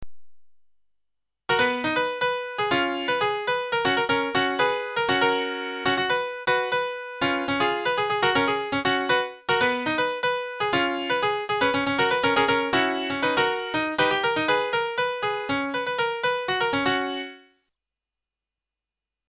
Да... И, пусть будет ещё и набросок ВСТУПЛЕНИЯ (с 01-ой по ~17 сек.), получившийся из последнего MP3 (при переводе его в MIDI, и обратно в MP3: